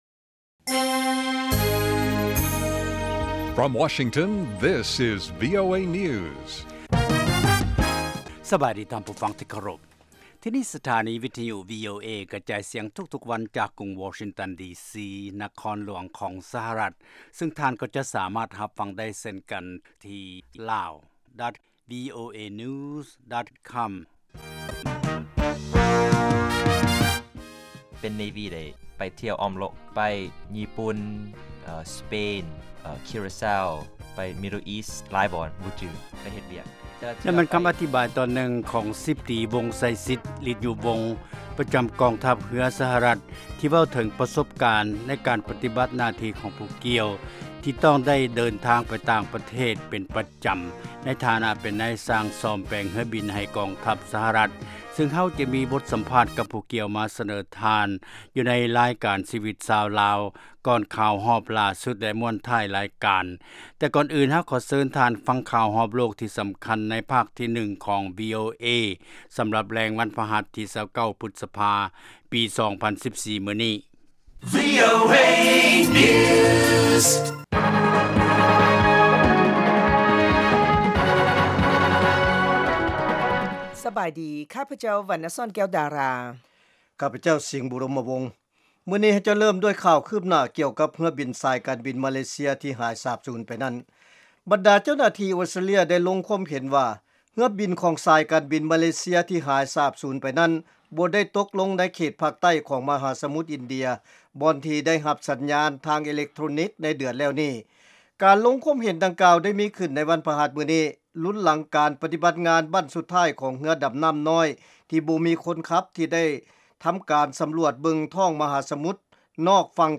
ວີໂອເອພາກພາສາລາວ ກະຈາຍສຽງທຸກໆວັນ ເປັນເວລາ 30 ນາທີ.